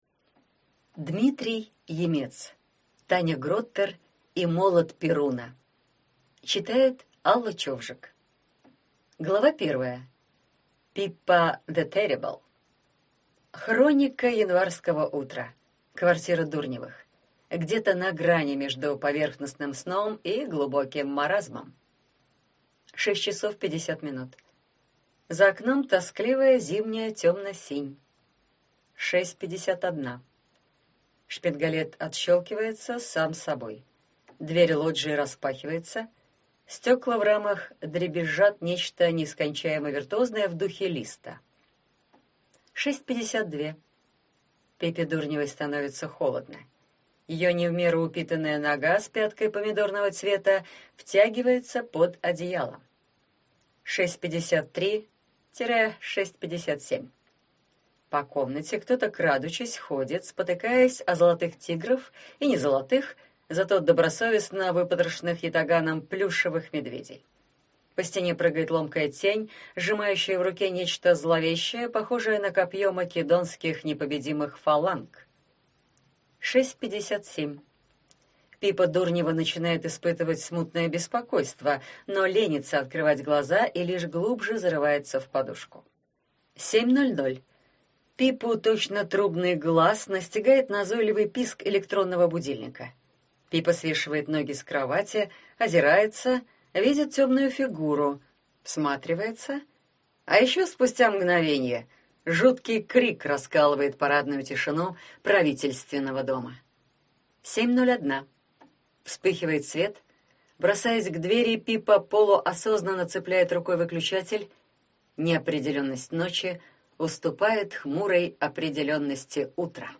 Аудиокнига Таня Гроттер и молот Перуна | Библиотека аудиокниг